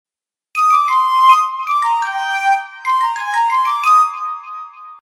piccolo.ogg